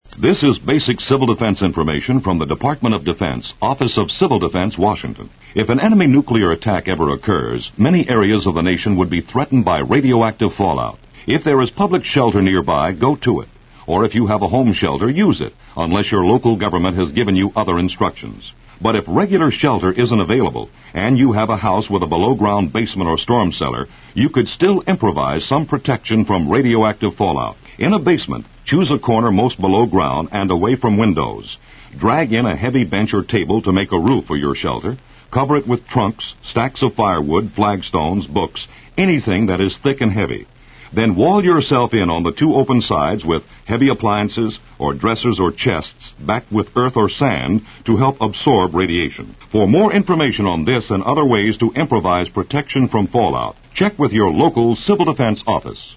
These radio spots were taken from the In Time of Emergency Radio kit #1 released by the Office of Civil Defense in 1968.